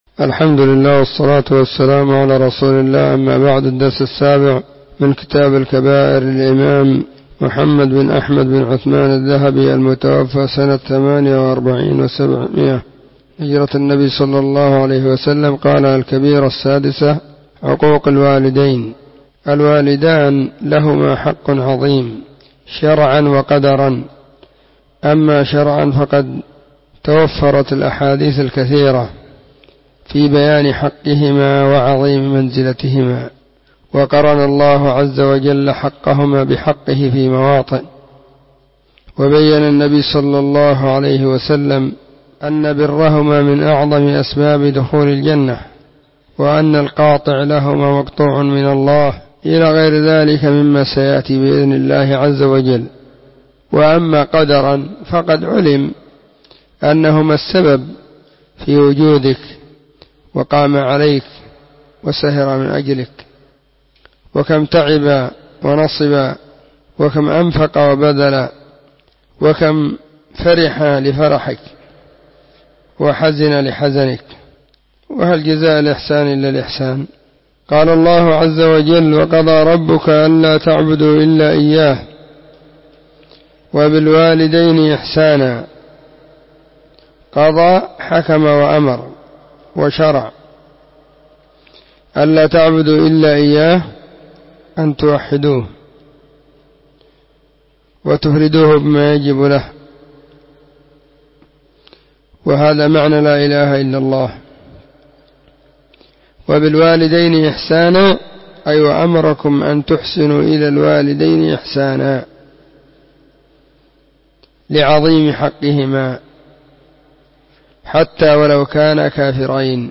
🕐 [بين مغرب وعشاء – الدرس الثاني]
📢 مسجد الصحابة – بالغيضة – المهرة، اليمن حرسها الله.